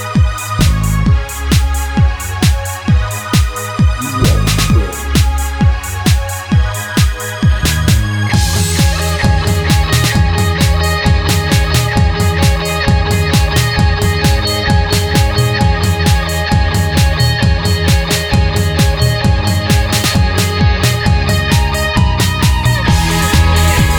no Backing Vocals Indie / Alternative 3:42 Buy £1.50